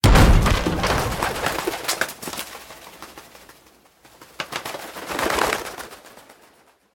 breakin.ogg